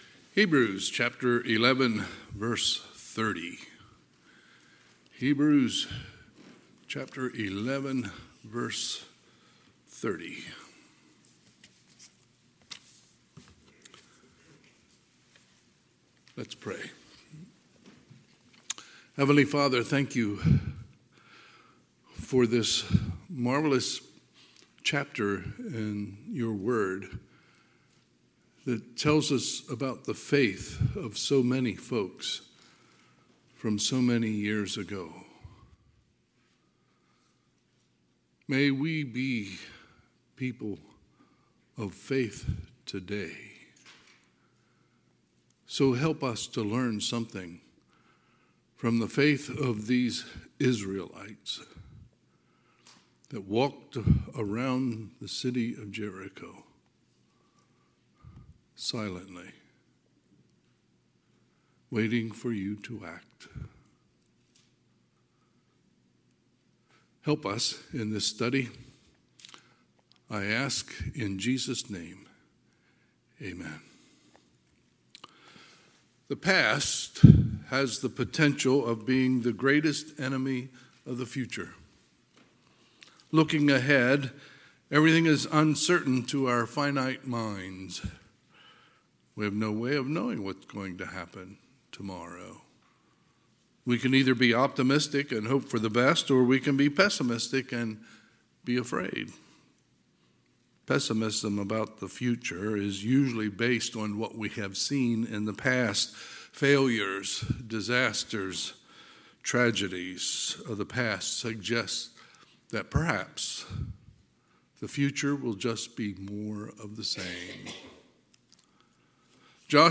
Sunday, November 10, 2024 – Sunday AM
Sermons